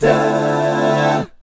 1-DMI7  AA.wav